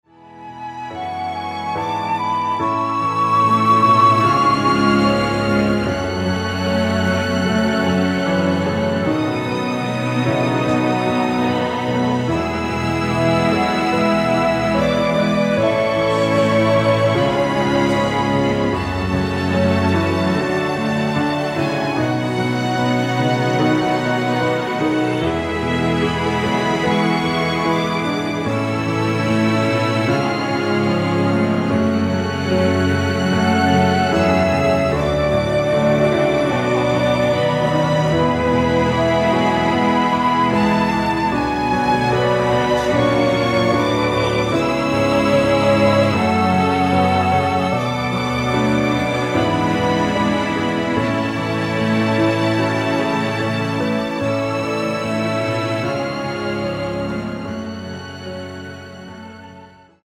원키 코러스 포함된 MR입니다.(미리듣기 확인)
앞부분30초, 뒷부분30초씩 편집해서 올려 드리고 있습니다.